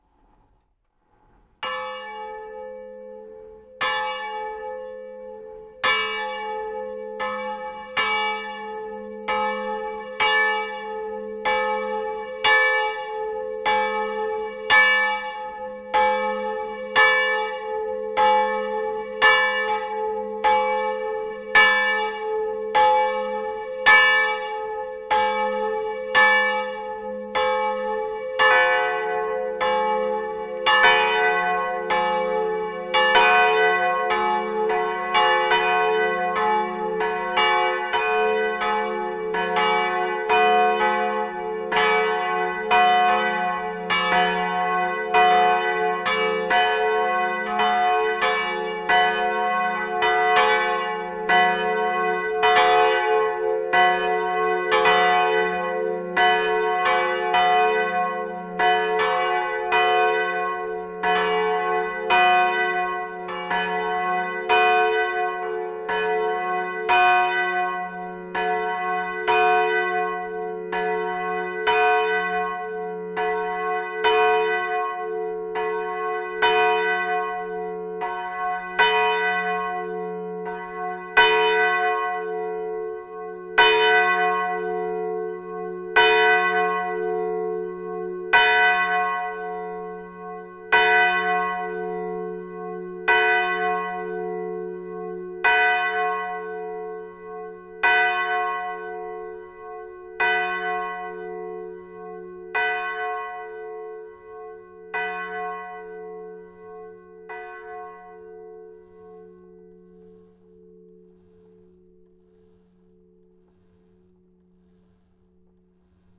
Les cloches d'Isières (3/3)
Vous pouvez écouter chacune des cloches présentes en clocher ci-dessous (volée manuelle) :
L'église d'Isières comporte trois cloches : une Drouot de 1817 et deux Michiels de 1953.